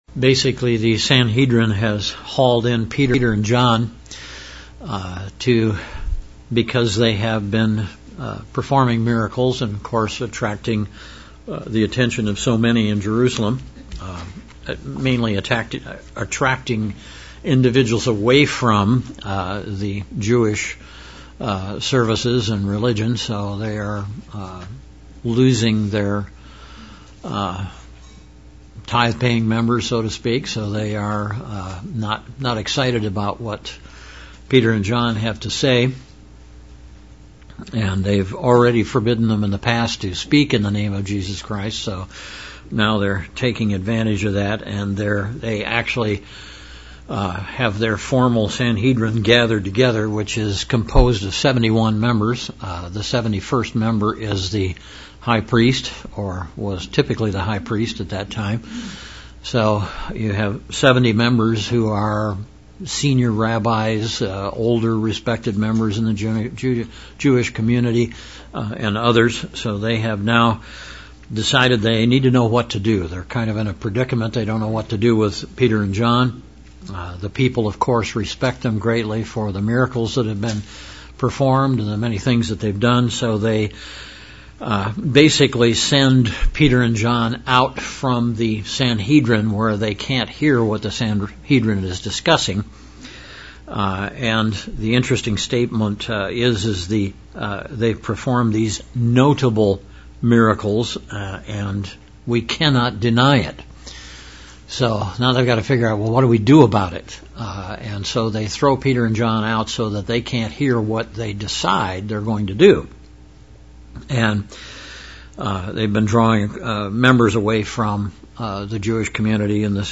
Bible Study: Acts of the Apostles - Chapter 4:16 - 5:11
Given in Central Illinois